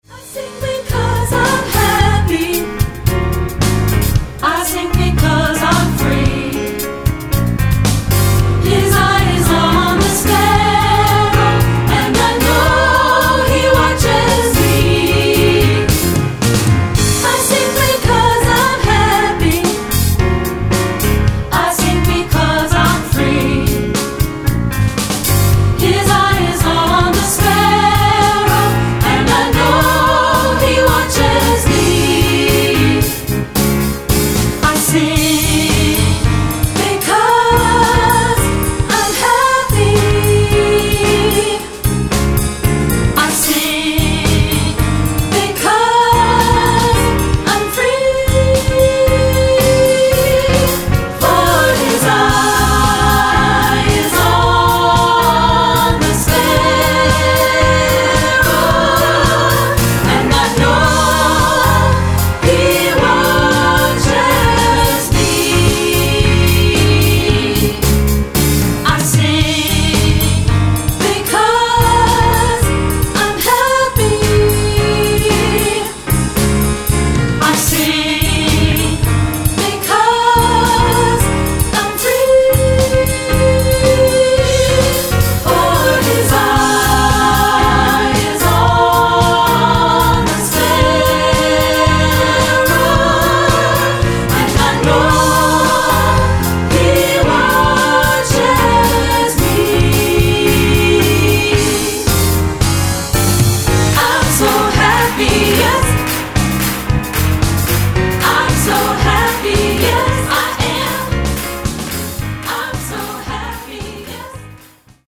Now available for treble voices!